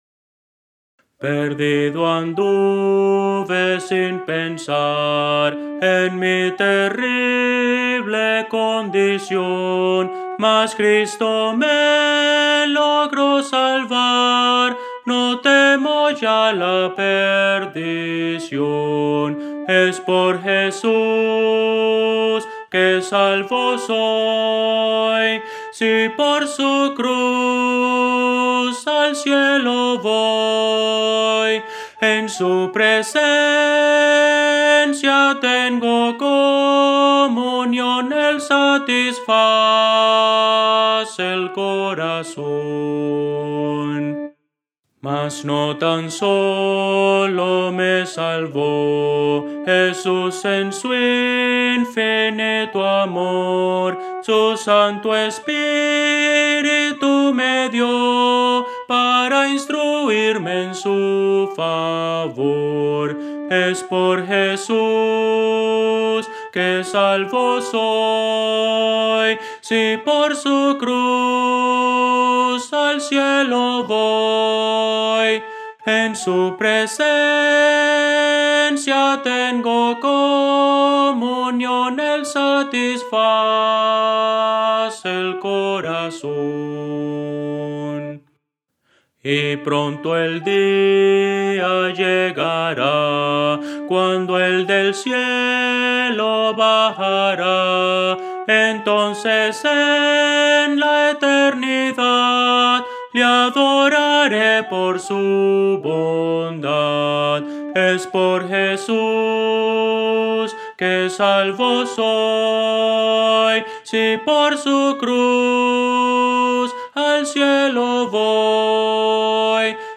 A capela – 4 Voces
Voces para coro
Soprano – Descargar